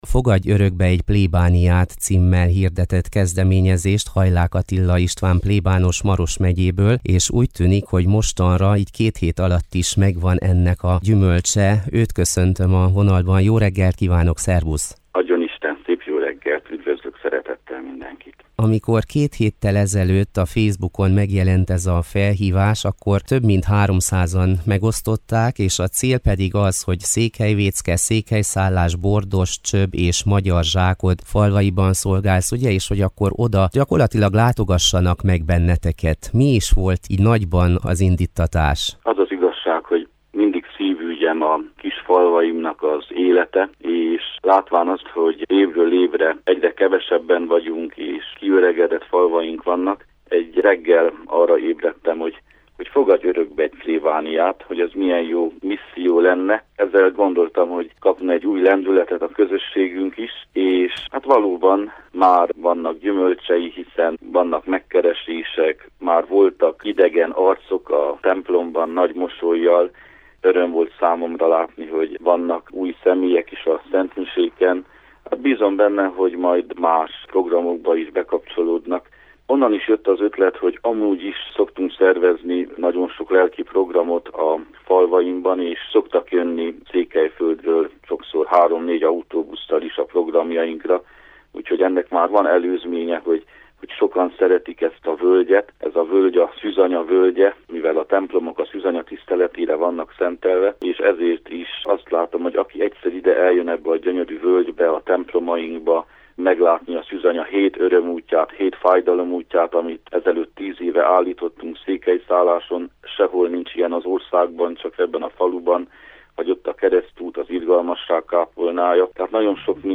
Beszélgetőtárs